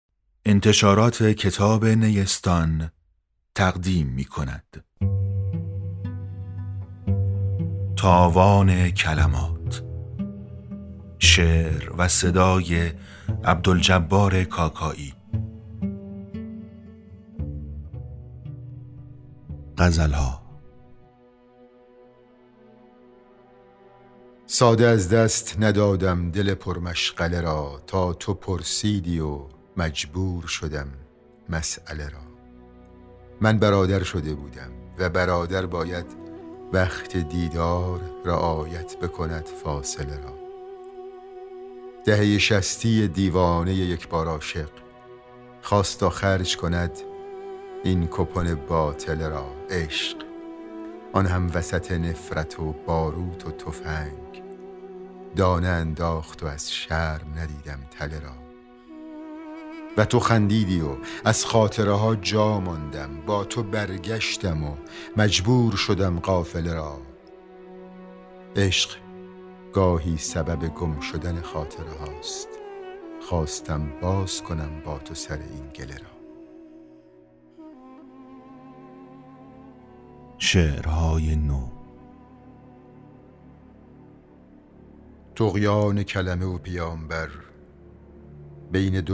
دو کتاب صوتی با اشعار و صدای عبدالجبار کاکایی منتشر شد
هر دو کتاب صوتی «تاوان کلمات» و «عذاب دوست داشتن» که با صدای خود عبدالجبار کاکایی منتشر شده‌اند، در سایت و اپلیکیشن «نوار» و «کتابراه» و «فیدیبو» قابل دسترسی و خرید است.